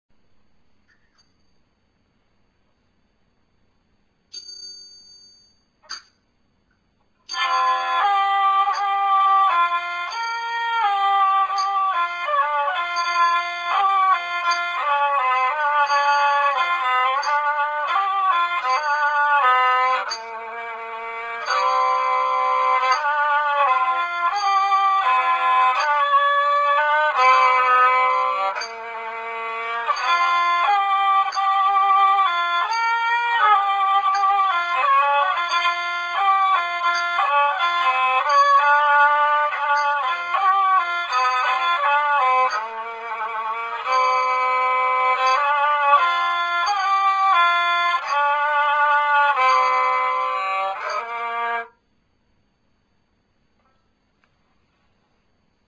Khryang Sii : bowed instruments
As for the saw sam sai of Thai, it is a bowed instrument with a somewhat triangular body and one spiked leg.
The size and pitch of the instrument depend on the size of the coconut shell which may be found.